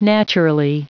Prononciation du mot naturally en anglais (fichier audio)